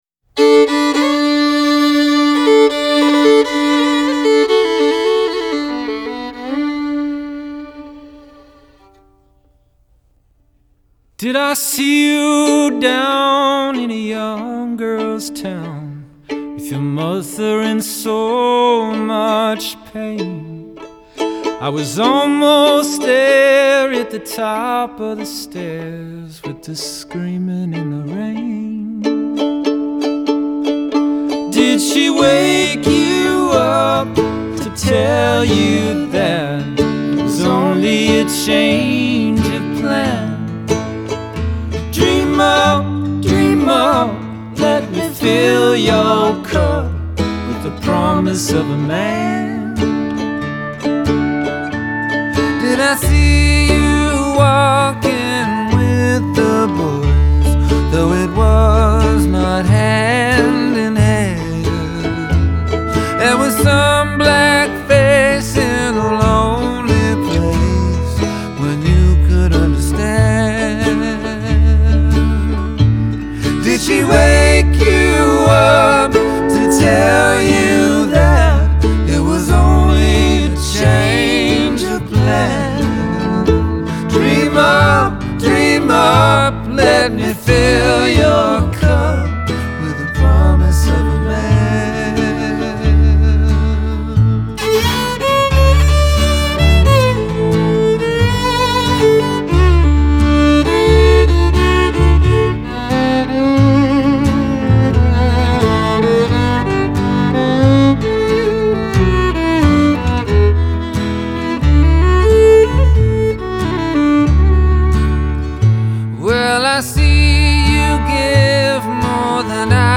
Genre : Folk